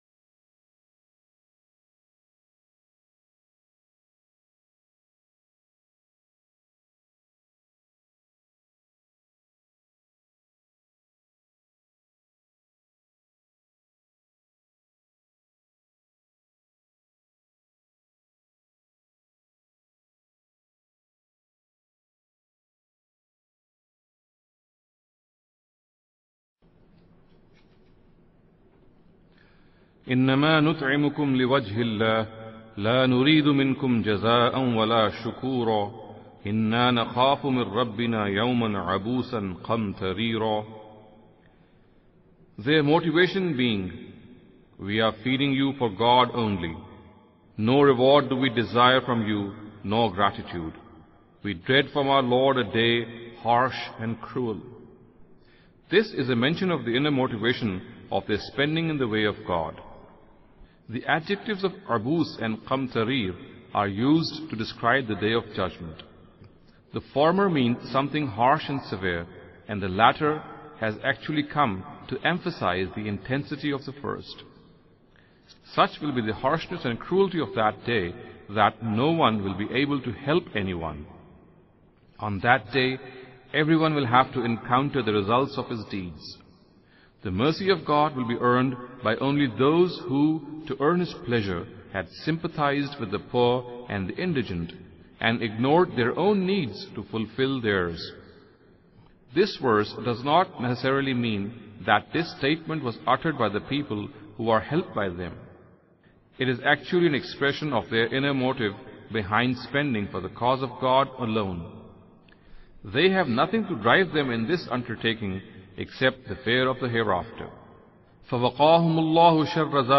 Dars-e-Qur'an